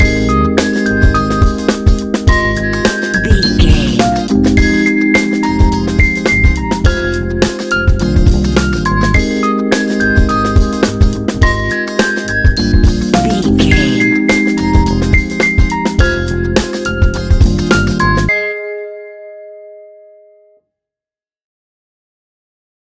Ionian/Major
G♯
laid back
Lounge
new age
chilled electronica
ambient